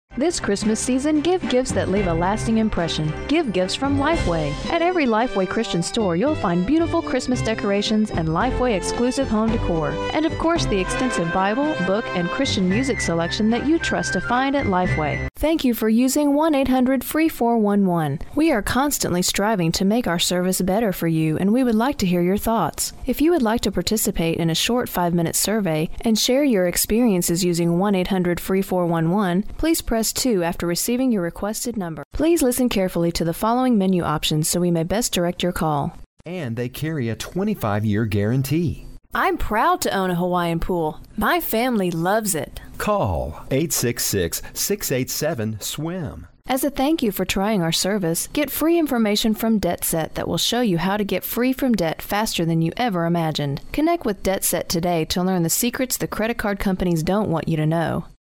Soothing, Comfortable, Friendly